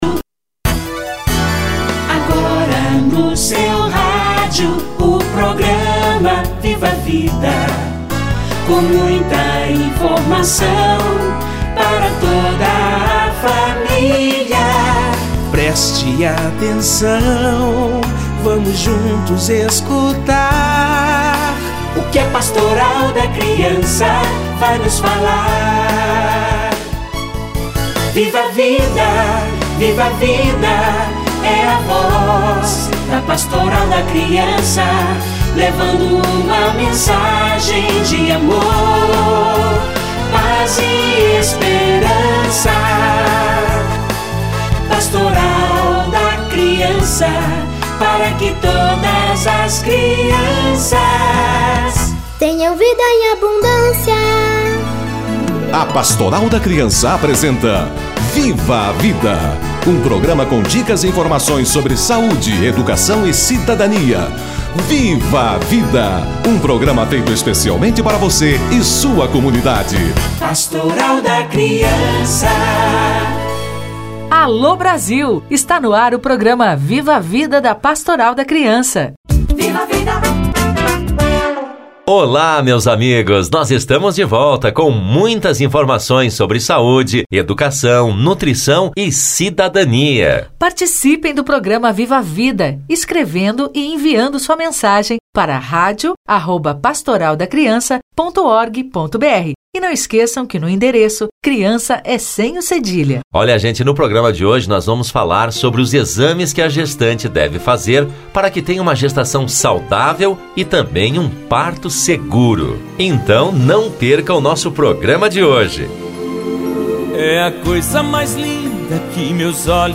Exames de gestante - Entrevista